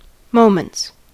Ääntäminen
Ääntäminen US Tuntematon aksentti: IPA : /ˈməʊmənt/ Haettu sana löytyi näillä lähdekielillä: englanti Moments on sanan moment monikko.